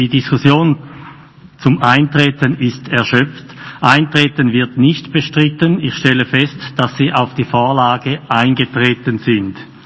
Session des Kantonsrates vom 15. bis 17. Februar 2021